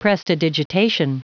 Prononciation du mot prestidigitation en anglais (fichier audio)
prestidigitation.wav